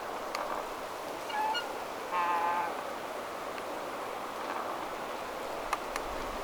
läheltä äänitettynä -
kuin metsähanhen ääni??
teltan_kurkilauta_matkii_metsahanhea.mp3